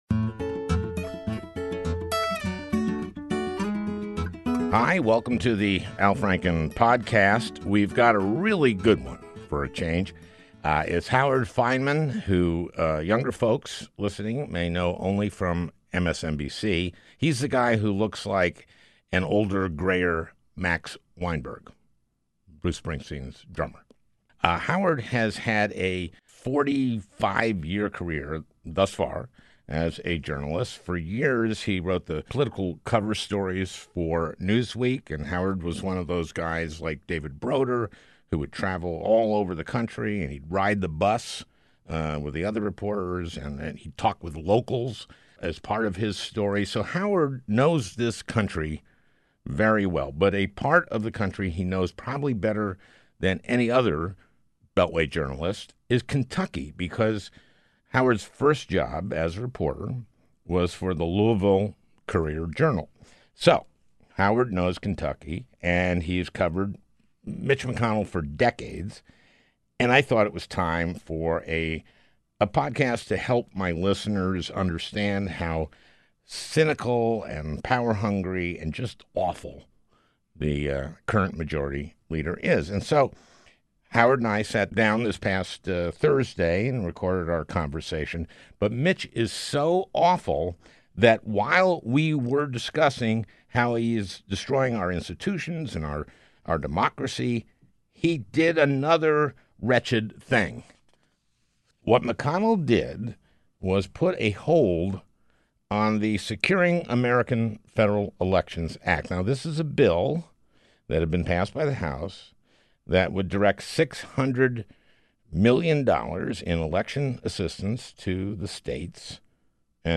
A Conversation With Howard Fineman